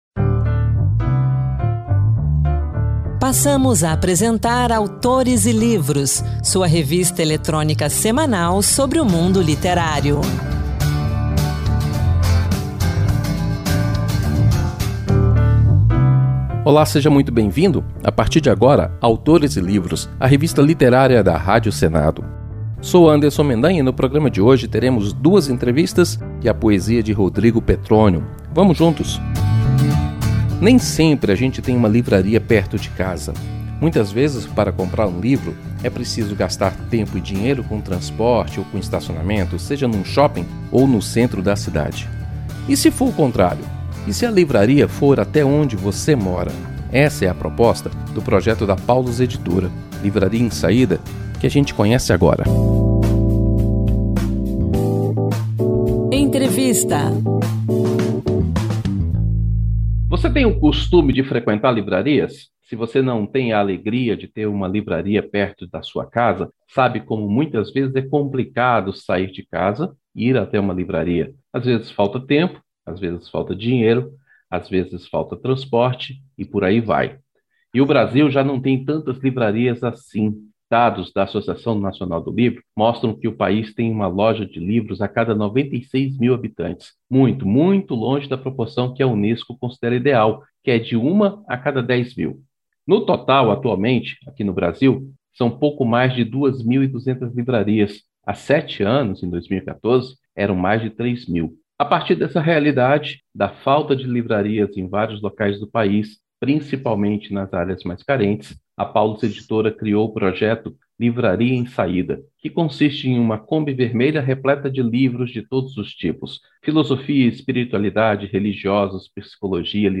O programa traz também uma conversa